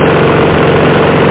JET.mp3